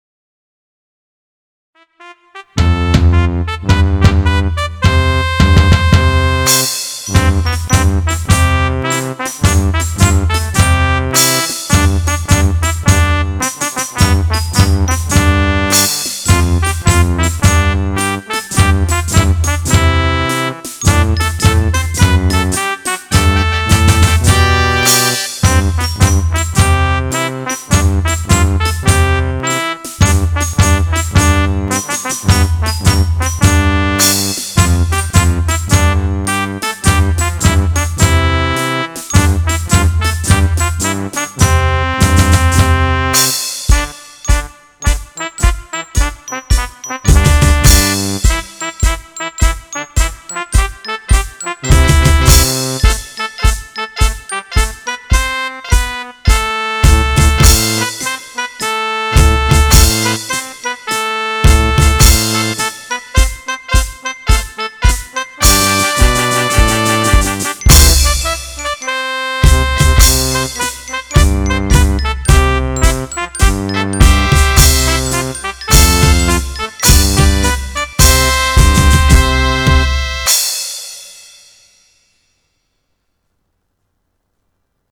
Mars SMKN 2 Salatiga